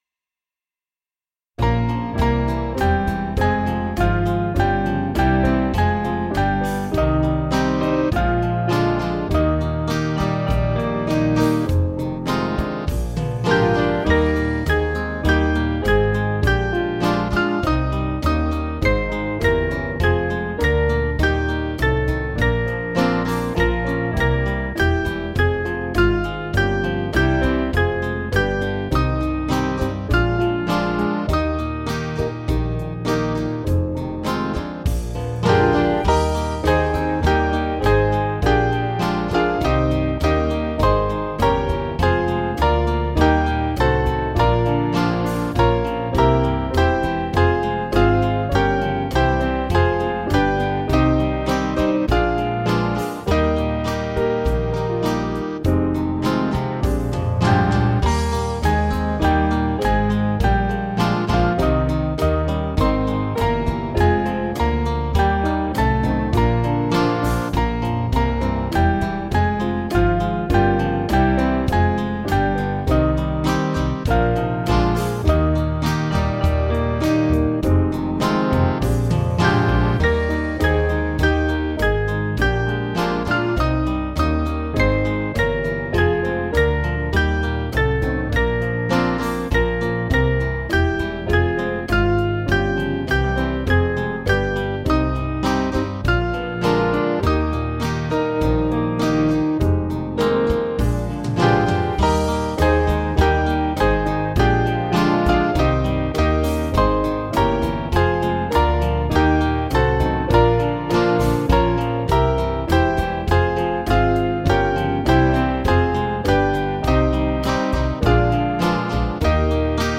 Small Band
(CM)   7/Eb 486.5kb